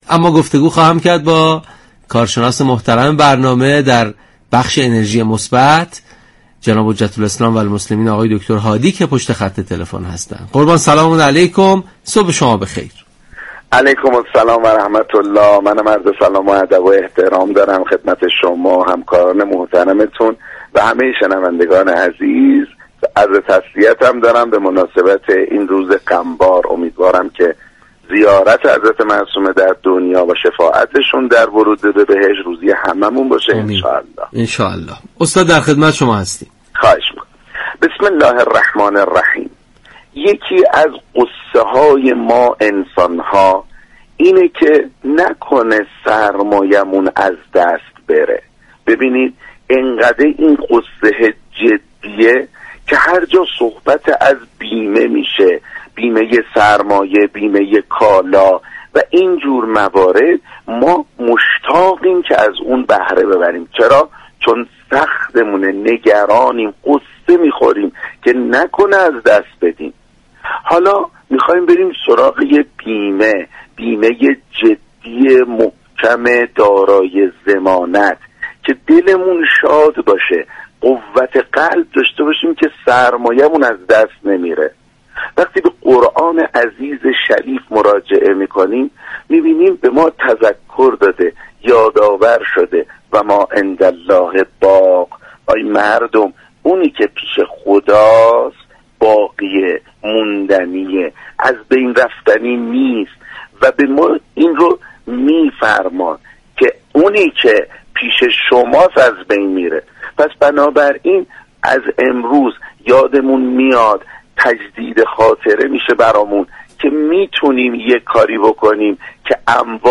مجله صبحگاهی "تسنیم" رادیو قرآن با رویكرد اطلاع رسانی همراه با بخش هایی متنوع، شنبه تا پنجشنبه از این شبكه رادیویی به صورت زنده تقدیم شنوندگان می شود.